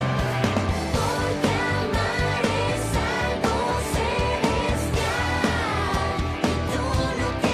Celestial Portal Opening Efecto de Sonido Descargar
Celestial Portal Opening Botón de Sonido